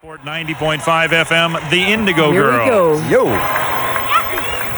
01. radio station id (0:04)